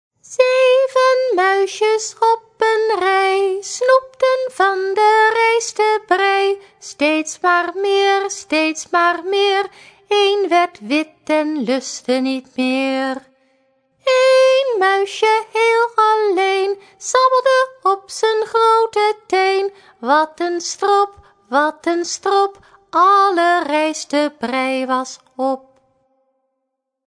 liedjes voor peuters en kleuters
Zang